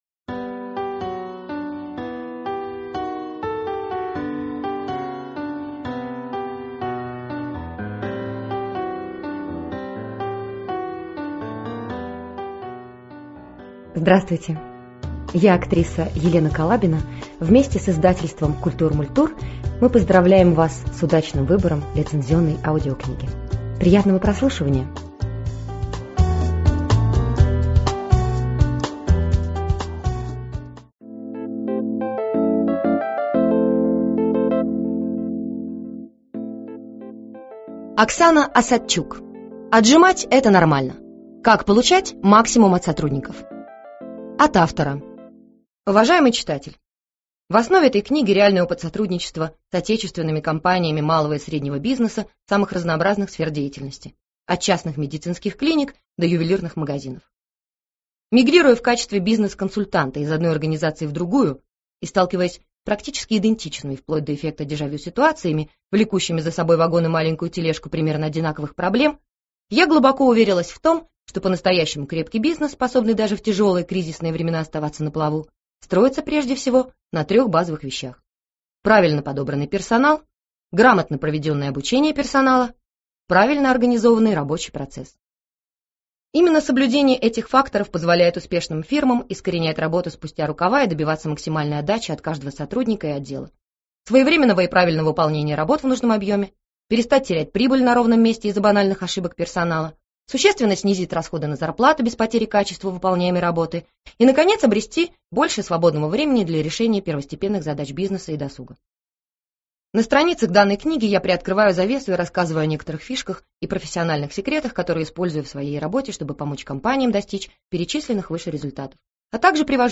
Аудиокнига Отжимать – это нормально. Как получать максимум от сотрудников | Библиотека аудиокниг